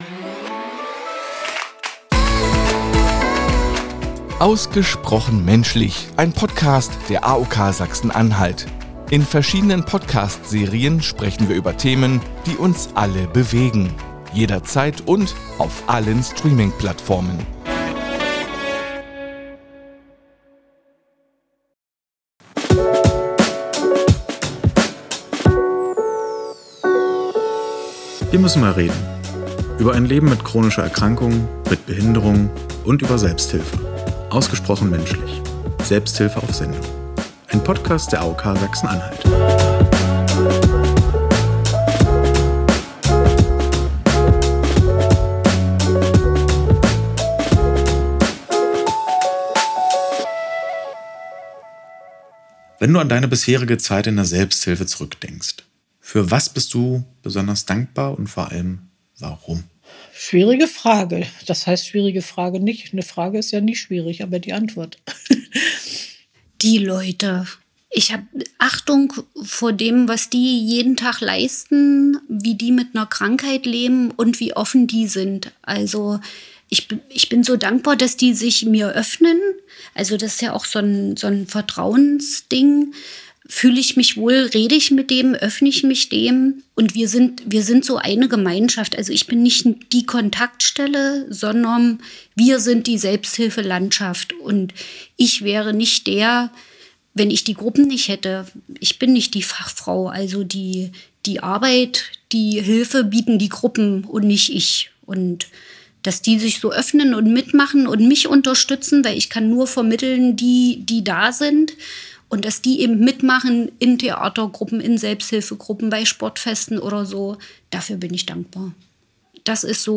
Dann folgt heute ein besonderer Jahres- und Staffelabschluss mit einem Zusammenschnitt all unserer Gäste, denen eine Frage gestellt wurde: »Wofür bist DU dankbar?«